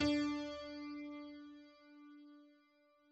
Ton "D"